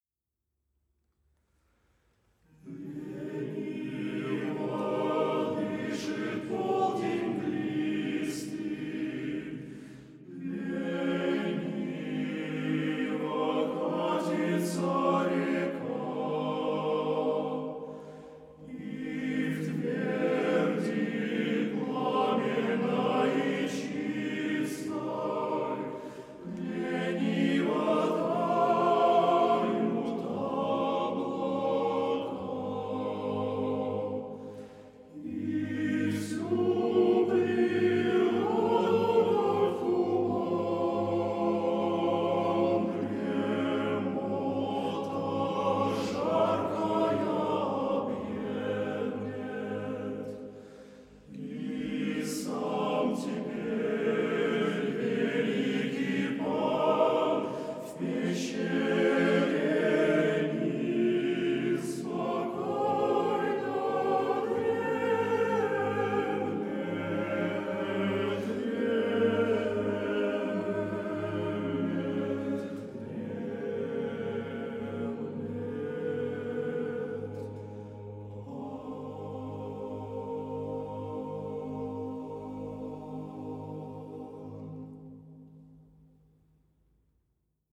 hor-KGF-KSP-Male-Choir-Polden-Cezar-Kyui-Fedor-Tyutchev-stih-club-ru.mp3